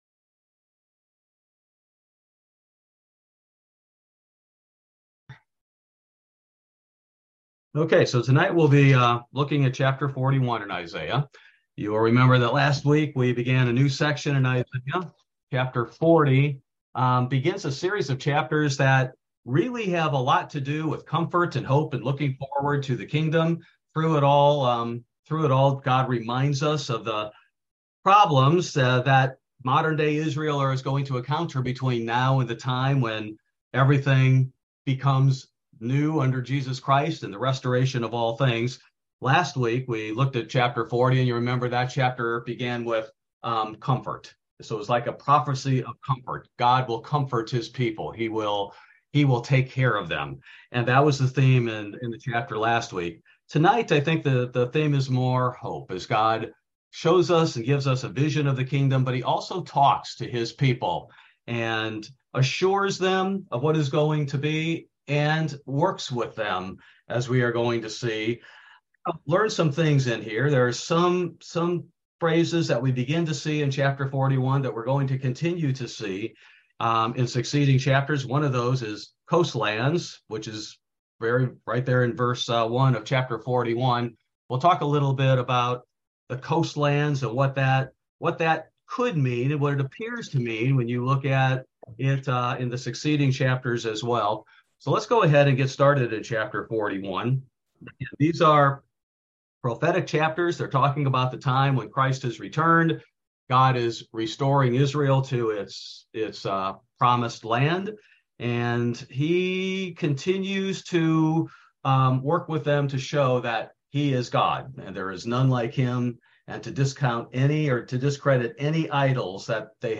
This verse by verse Bible Study primarily covers Isaiah 41: A Prophecy of Hope